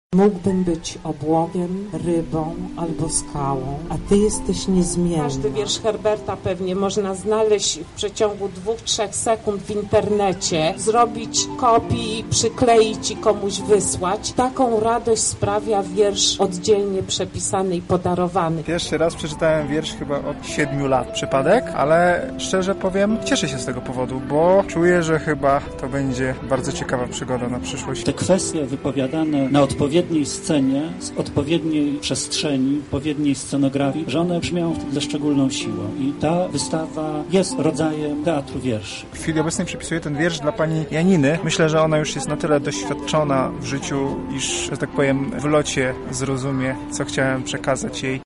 Na miejscu otwarcia wystawy „Nagła wyspa. Herbert 2019” była nasza reporterka: